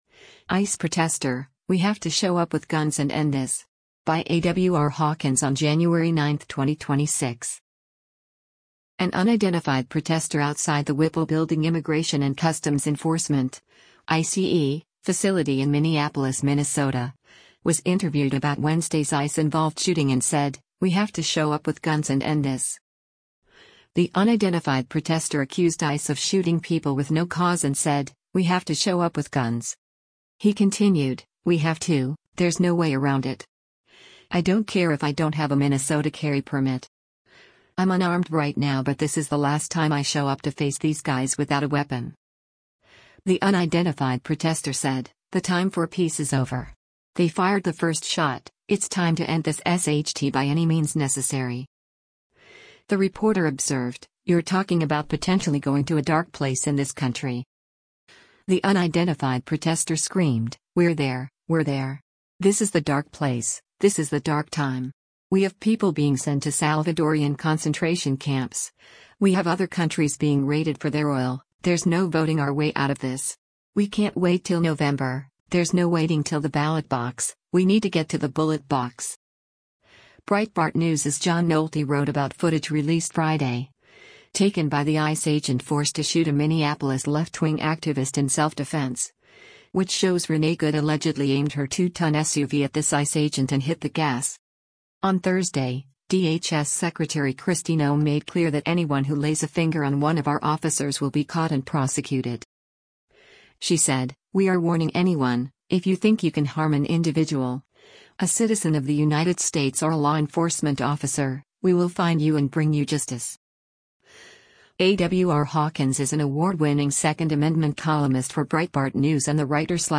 An unidentified protester outside the Whipple Building Immigration and Customs Enforcement (ICE) facility in Minneapolis, Minnesota, was interviewed about Wednesday’s ICE-involved shooting and said, “We have to show up with guns and end this.”
The reporter observed, “You’re talking about potentially going to a dark place in this country.”